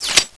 scan_deactivate.wav